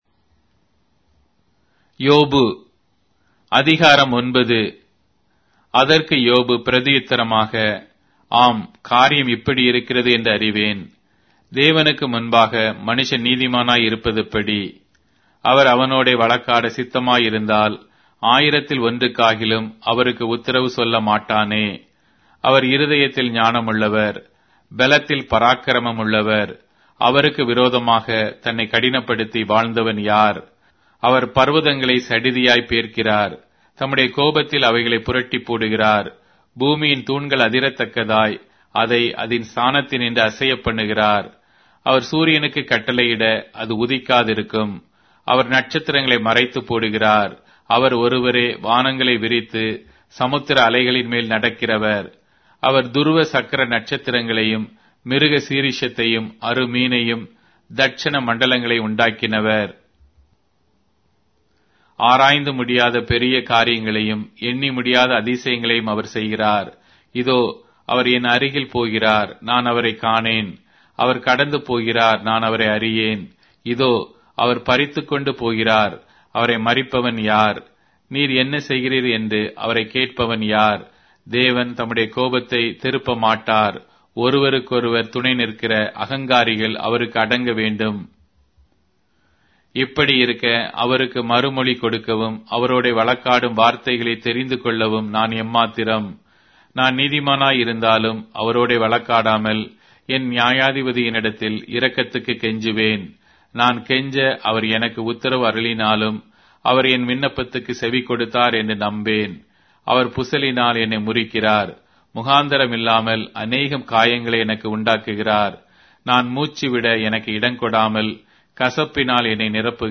Tamil Audio Bible - Job 33 in Orv bible version